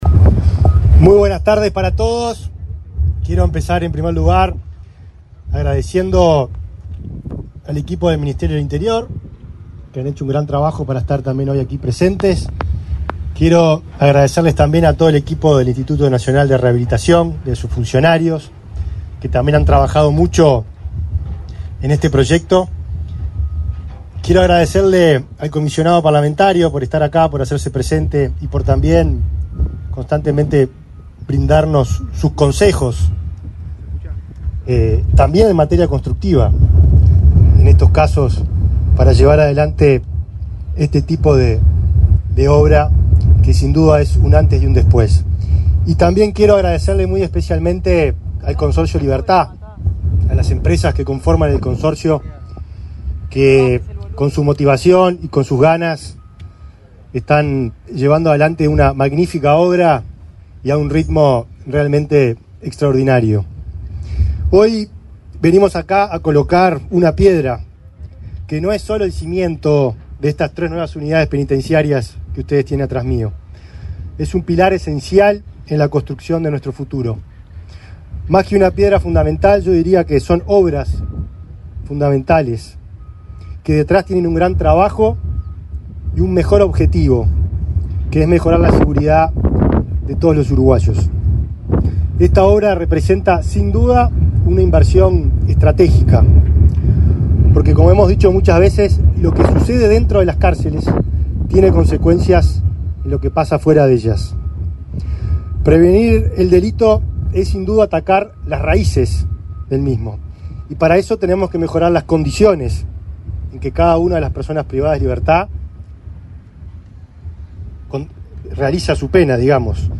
Palabras del ministro del Interior, Nicolás Martinelli
Palabras del ministro del Interior, Nicolás Martinelli 08/10/2024 Compartir Facebook X Copiar enlace WhatsApp LinkedIn Este martes 8 en la ciudad de Libertad, departamento de San José, el ministro del Interior, Nicolás Martinelli, encabezó el acto de colocación de la piedra fundamental de la construcción de las unidades penitenciarias números 27, 28 y 29, bajo el régimen de participación público-privada.